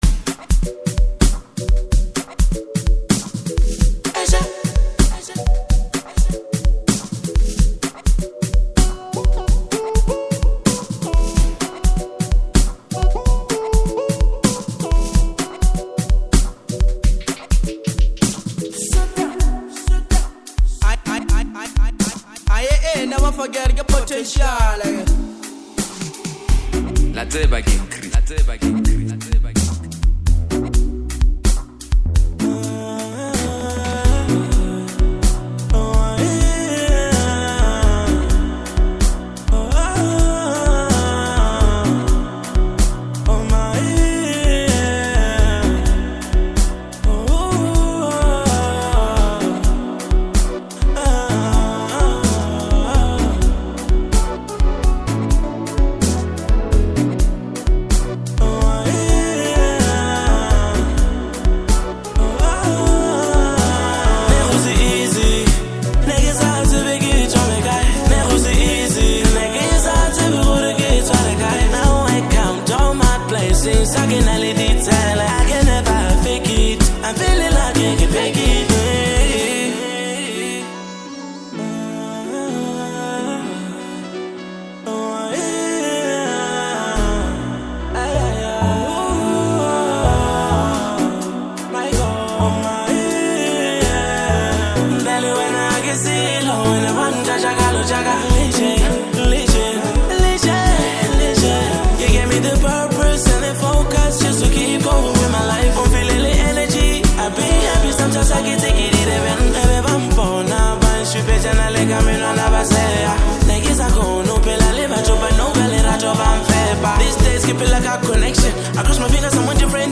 gospel infused Afrobeat anthem